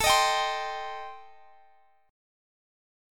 Am7b5 Chord
Listen to Am7b5 strummed